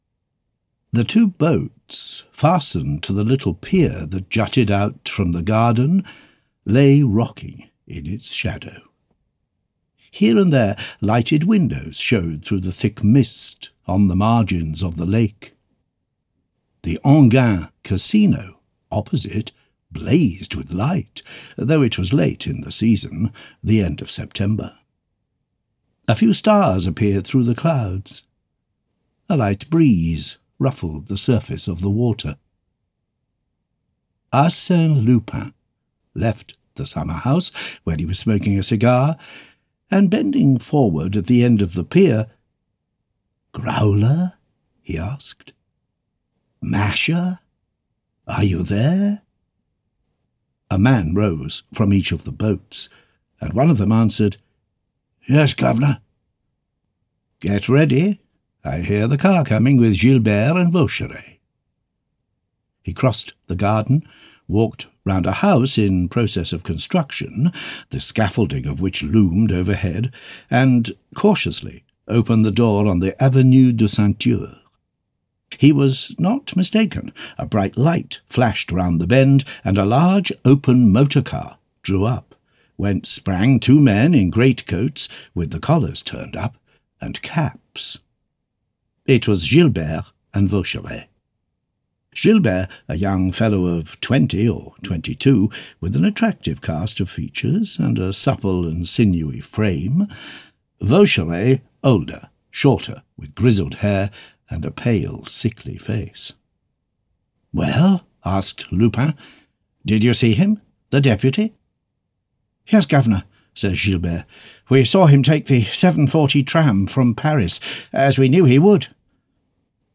The Crystal Stopper audiokniha
Ukázka z knihy